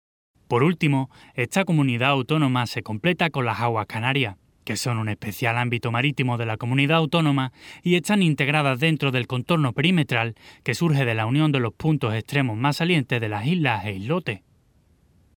Banco de voces de locutores con acentos regionales de Mallorca, Canarias y de Andalucía
Locutores andaluces. Locutores de Andalucía. Locutoras de Andalucía